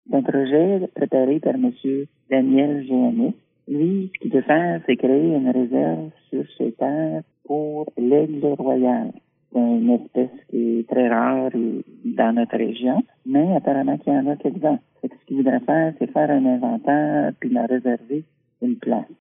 Il désire maintenant aménager une autre réserve, cette fois-ci, pour sauver et protéger les aigles royaux. La mairesse Cheryl Sage-Christensen commente :